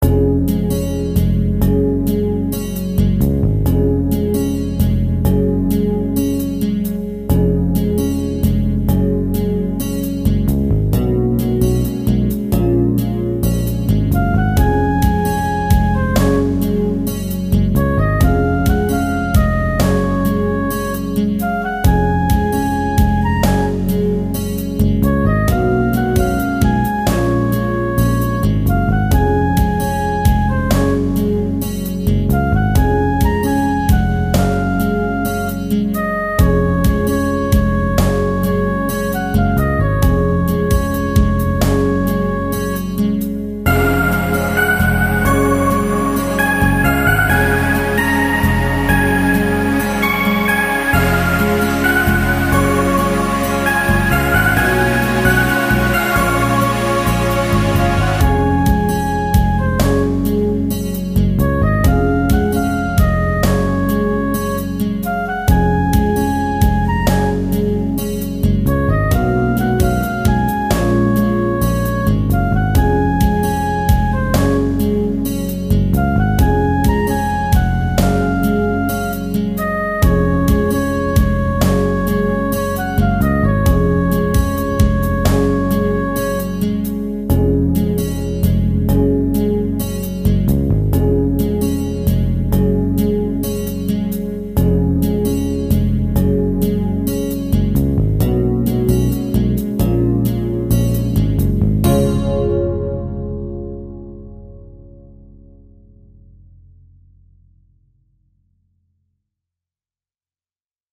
最近はＴＴＳというソフトシンセだけで作ってる。
それと、最近電子ピアノをよく使う。アコースティックなピアノと違って、角が取れてる感じの音が、周りの音とよく馴染んで良いから使うのかも。
ああ、そういや今回はオブリガート入れてないや。